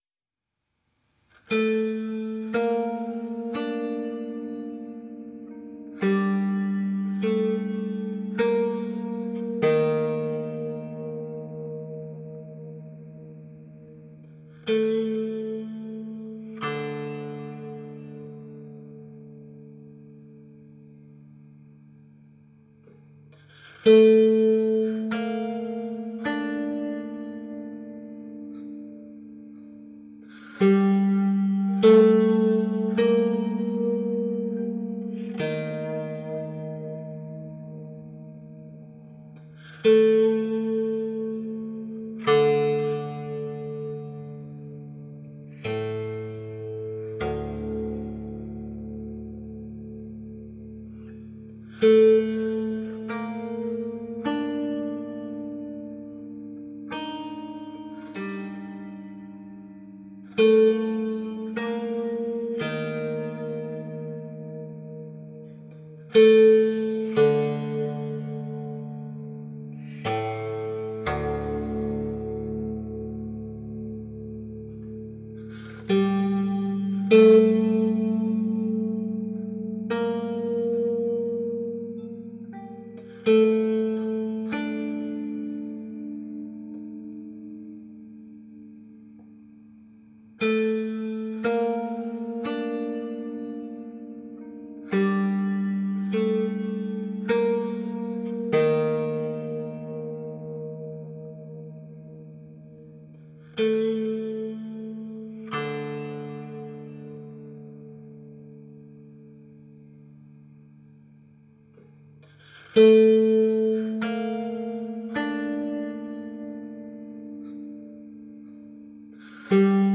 Japanisch gestimmtes Monochord